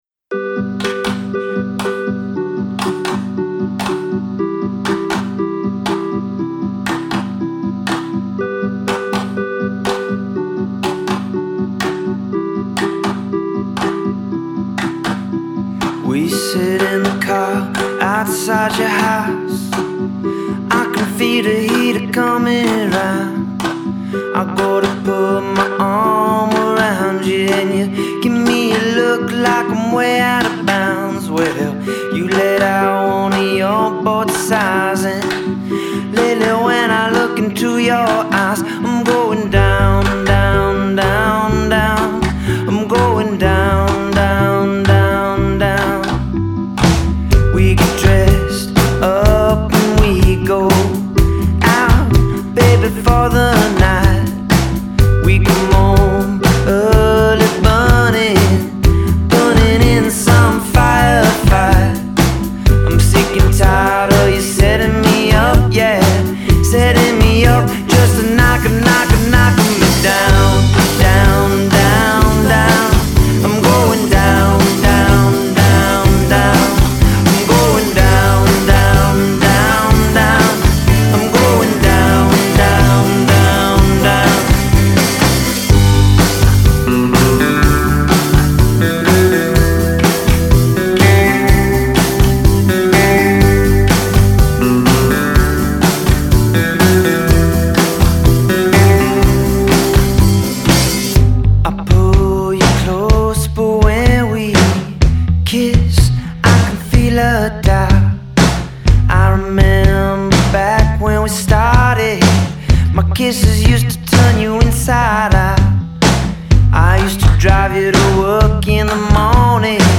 They laid this cover down for an iTunes session.